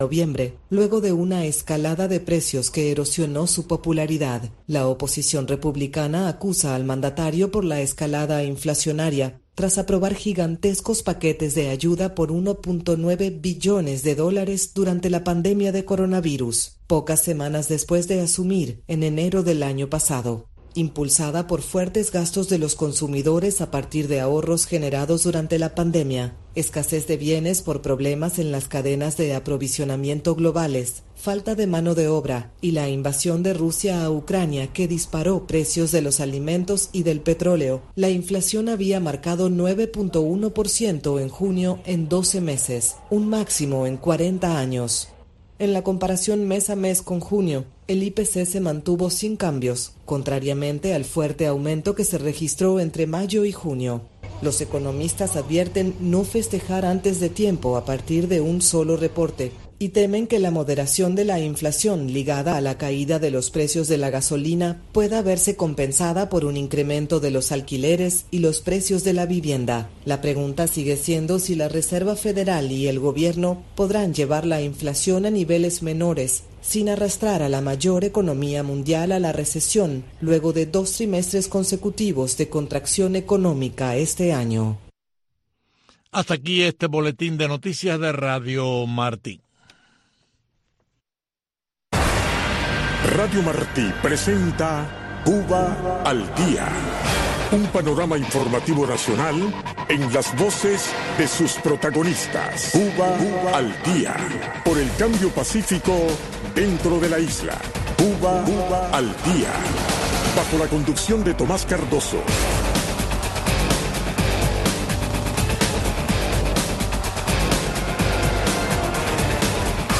en este espacio informativo en vivo, que marca el paso al acontecer cubano.